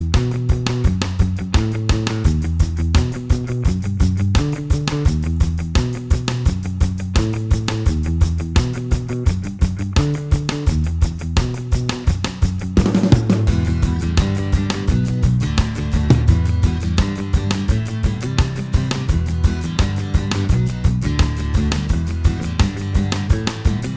Minus Main Guitar Ska 3:06 Buy £1.50